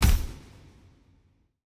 pgs/Assets/Audio/Fantasy Interface Sounds/Special Click 03.wav at master
Special Click 03.wav